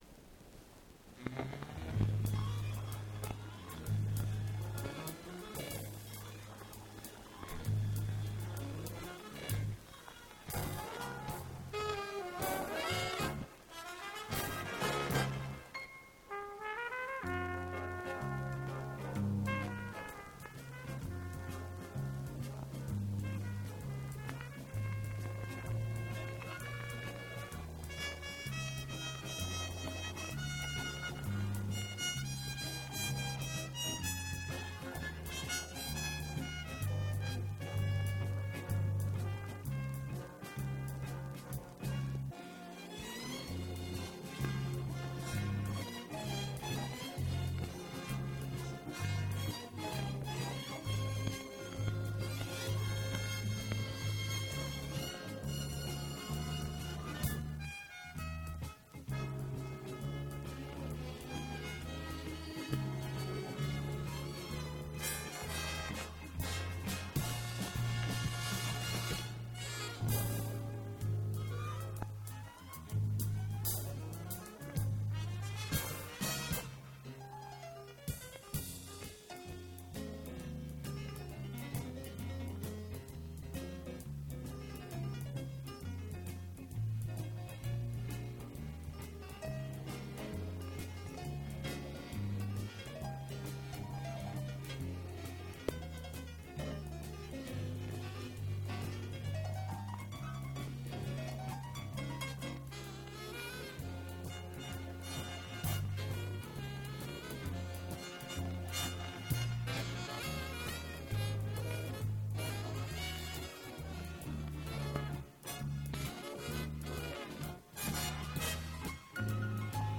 Запись 1978 год Дубль моно.